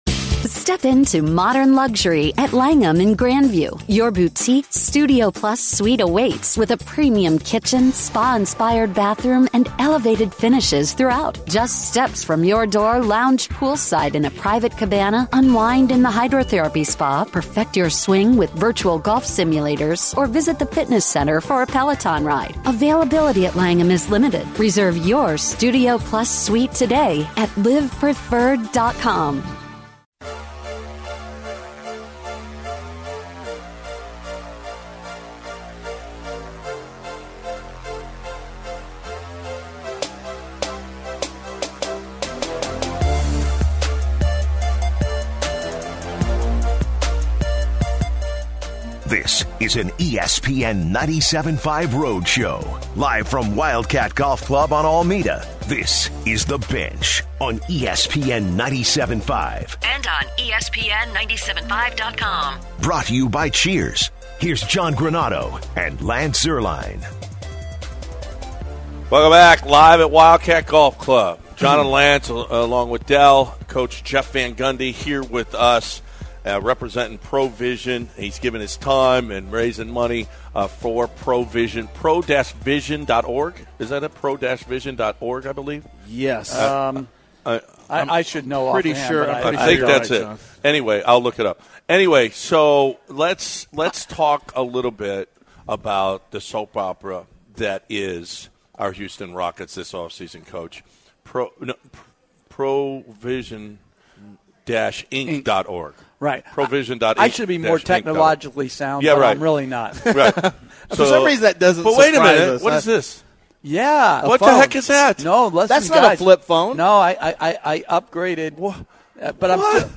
Jeff Van Gundy joins the crew live from Wildcat Golf Club. The guys ask JVG about the NBA Finals and what he thinks about all the Rockets controversy.